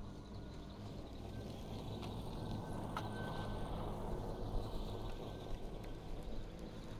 Zero Emission Snowmobile Description Form (PDF)
Zero Emission Subjective Noise Event Audio File (WAV)